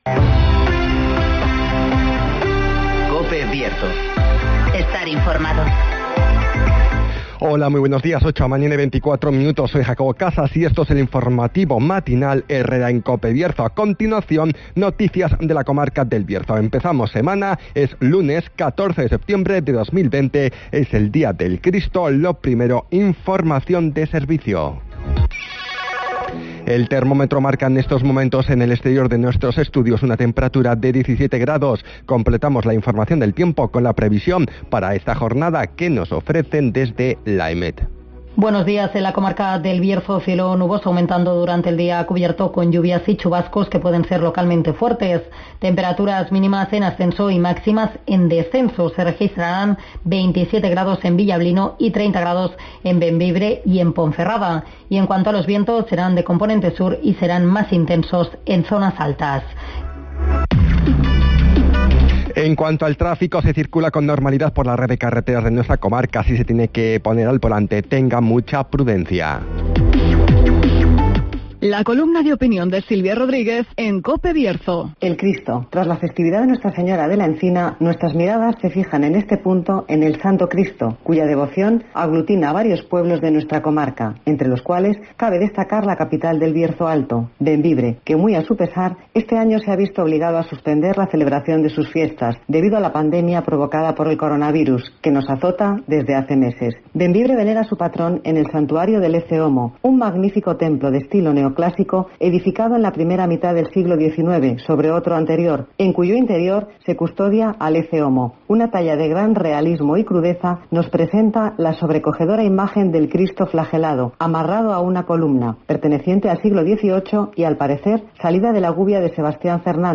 INFORMATIVOS
Conocemos las noticias de las últimas horas de nuestra comarca, con las voces de los protagonistas
-Declaraciones de Luis Tudanca